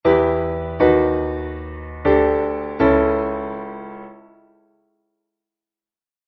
VI-III1-V-II1進行
VI-III1-V-II1.mp3